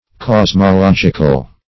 Cosmological \Cos`mo*log"ic*al\ (k?z`m?-l?j"?-kal), a.